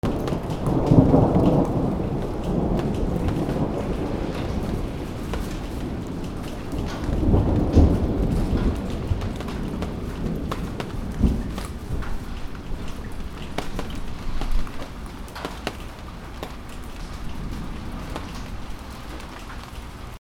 雷鳴
/ A｜環境音(天候) / A-35 ｜雷鳴
ブオーゴゴ